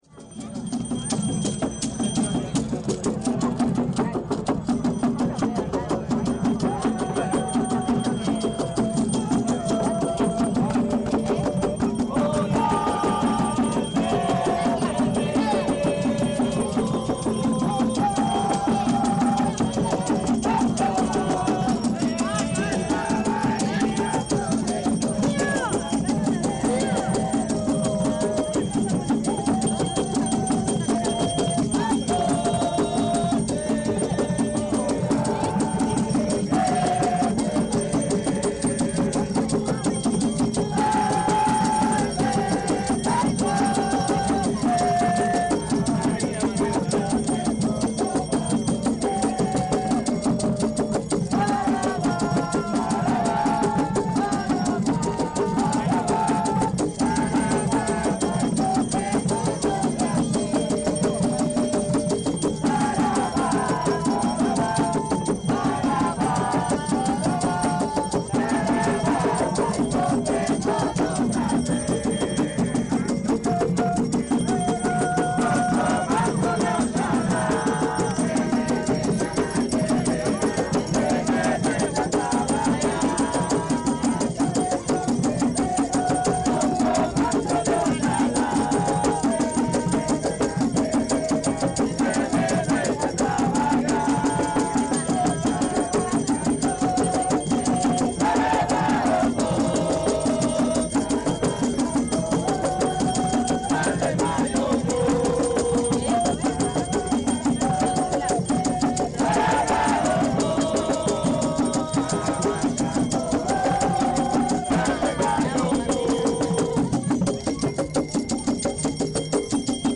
Miles de personas (entre fieles y curiosos), abarrotaron la playa Ramírez para rendirle culto a la diosa del mar Iemanjá.
Ya entrada la noche, se forman rondas donde los diferentes colectivos umbandistas practican su ritual con cánticos en portuñol, llevados por la cadencia de los tambores y el sonido estridente de las campanas.
Activar sonido ambiente.